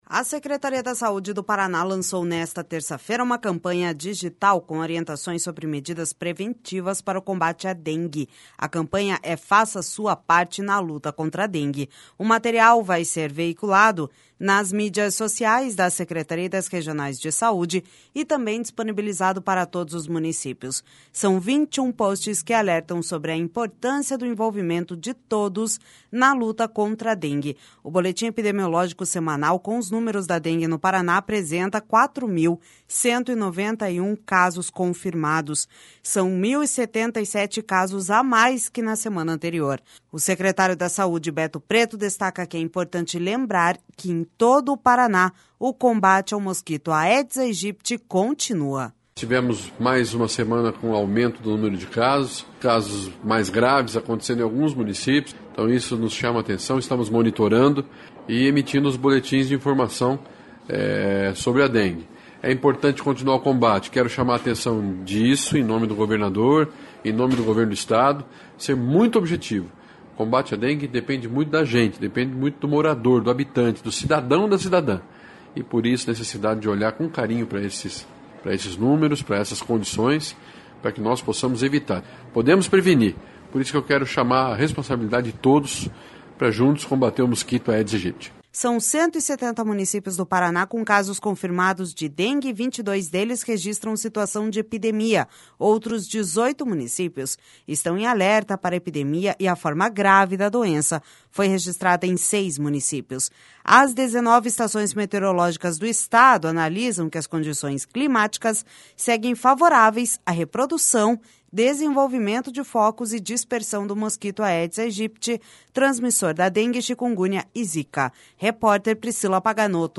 O secretário da Saúde, Beto Preto, destaca que é importante lembrar que em todo o Paraná, o combate ao mosquito Aedes aegypti continua.// SONORA BETO PRETO//São 170 municípios do Paraná com casos confirmados de dengue e 22 deles registram situação de epidemia.